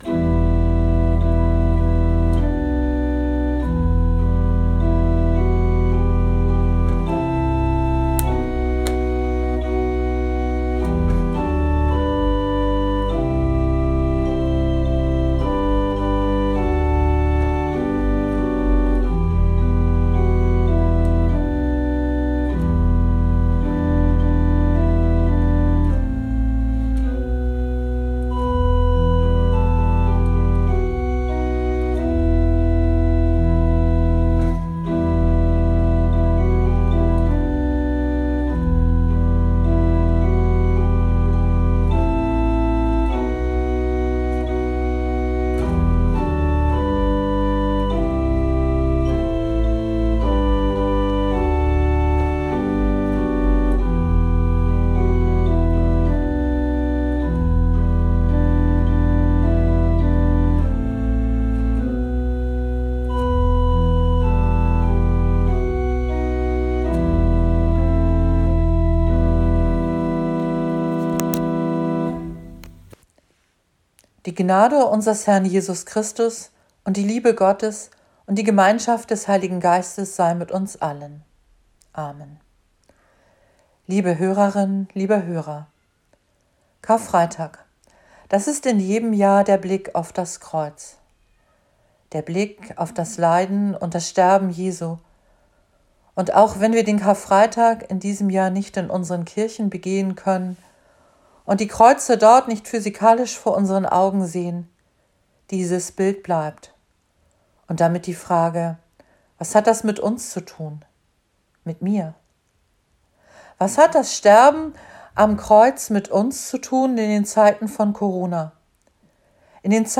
Gesamt: Vorspiel + Predigt